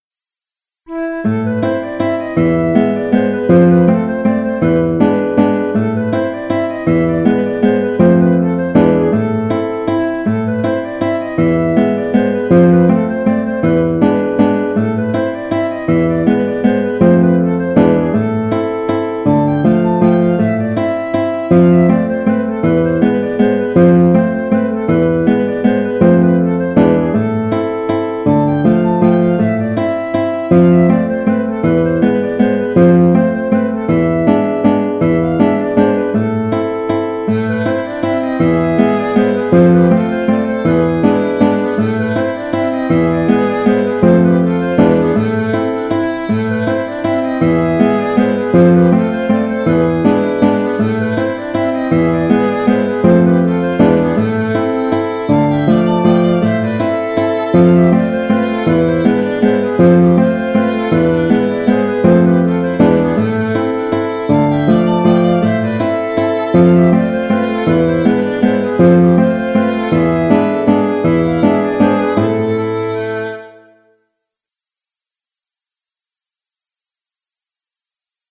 Fricotine (La) - Valse (Valse) - Compositions
Sur une même mélodie, aux accents de java, je propose deux interprétations différentes. L’une sur le rythme ternaire de la mazurka, l’autre sur un rythme de valse, plus rond et plus rapide.